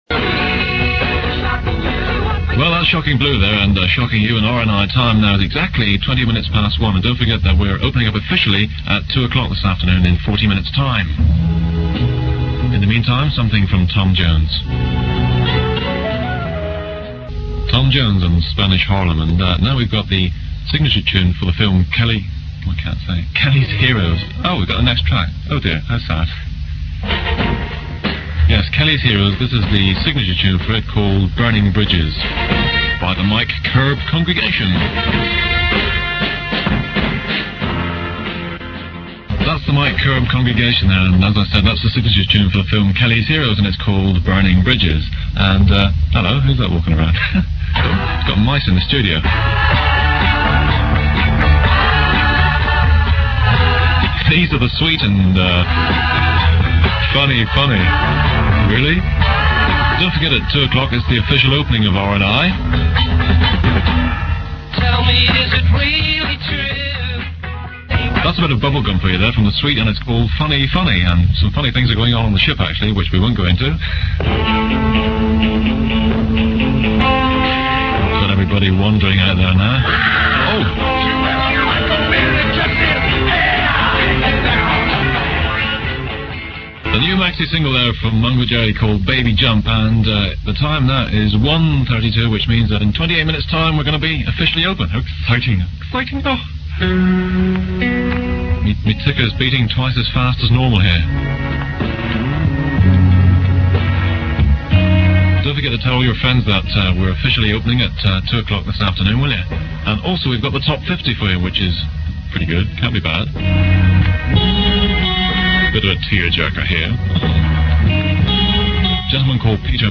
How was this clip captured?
on the last test transmission before the re-launch of Radio Northsea International, 21st February 1971